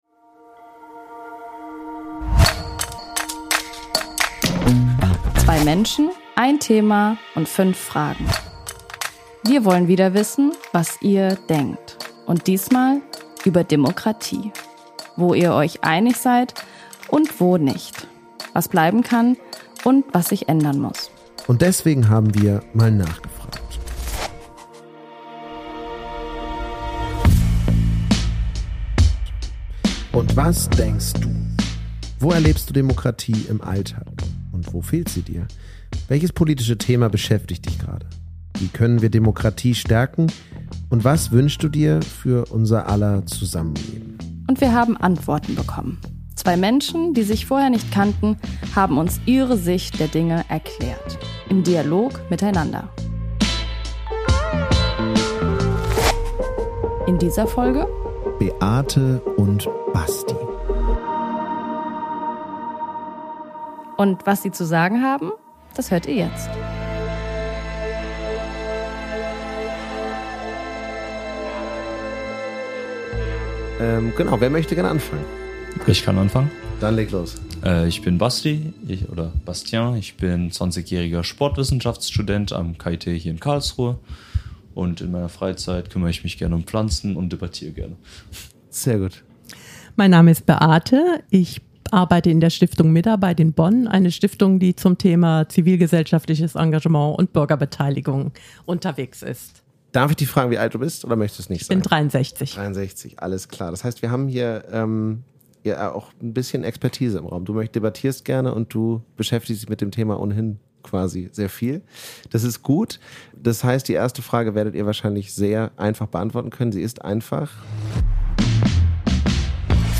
Beschreibung vor 1 Jahr 5 Fragen und 2 Menschen, die sich vorher nicht kannten: Wo erlebst du Demokratie im Alltag, wo fehlt sie dir und welche Themen beschäftigen dich gerade sonst noch so? Diese und weitere Fragen haben wir unterschiedlichen Menschen, die sich vorher nicht kannten, im Rahmen einer Straßenumfrage gestellt. Sie haben sie beantwortet, immer zu zweit, im Dialog miteinander.